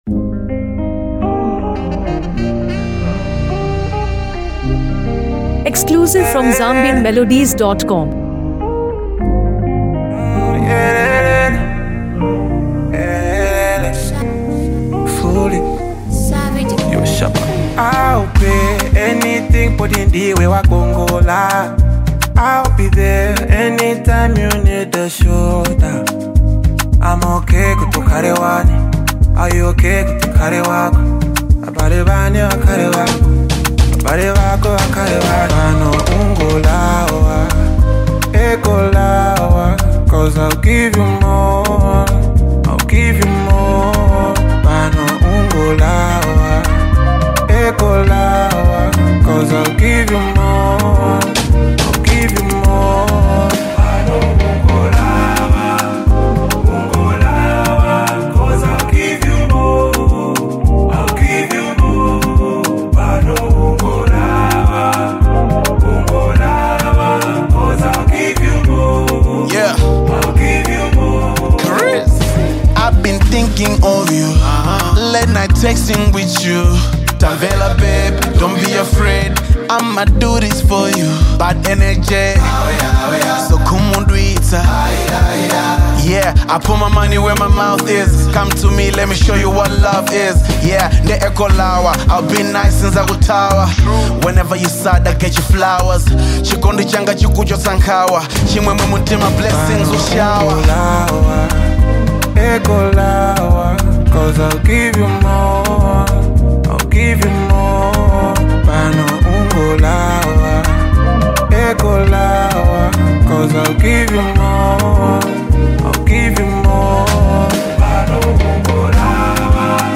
Afro-soul